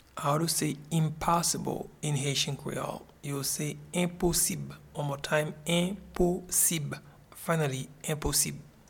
Pronunciation and Transcript:
Impossible-in-Haitian-Creole-Enposib.mp3